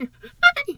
hyena_laugh_short_03.wav